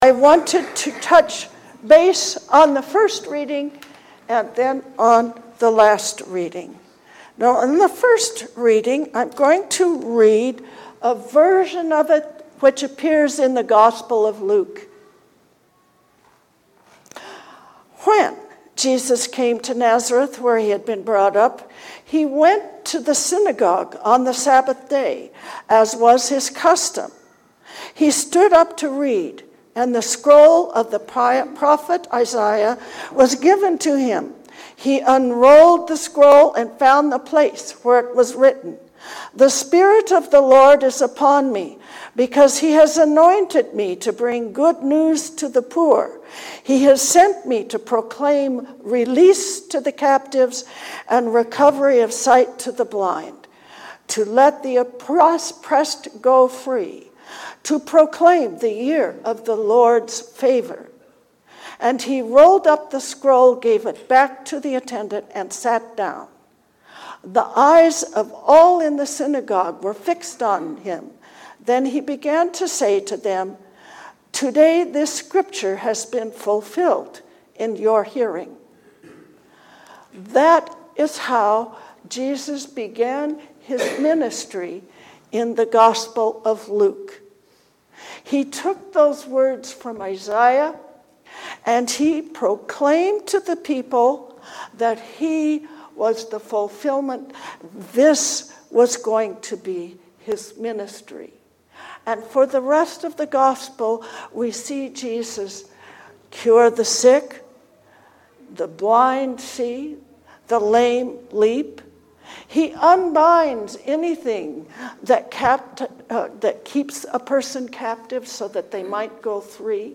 Being a community of Jesus-followers. A sermon for the 3rd Sunday of Easter